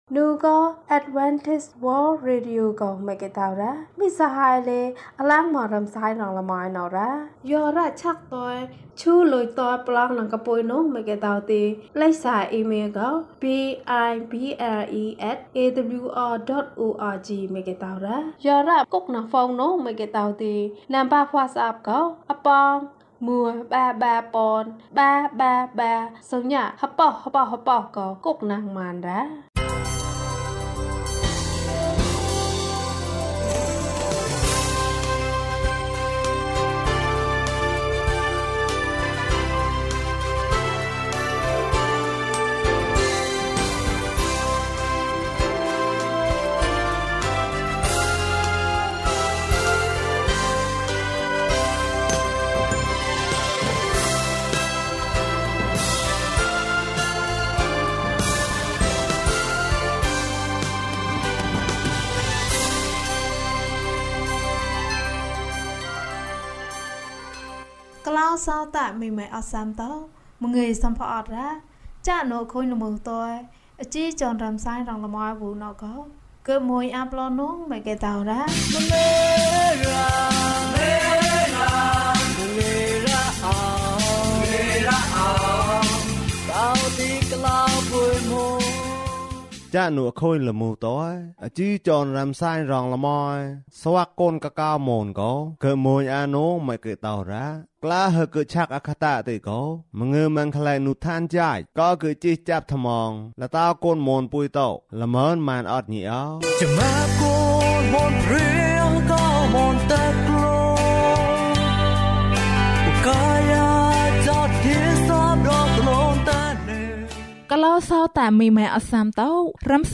ယေရှုသည် ကျွန်ုပ်၏အသက်ကို ကယ်တင်တော်မူ၏။ ကျန်းမာခြင်းအကြောင်းအရာ။ ဓမ္မသီချင်း။ တရား‌ဒေသနာ။